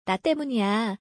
韓国語で「私のせいだよ」は「ナッテムニヤ（나 때문이야）」です。
ナッテムニヤ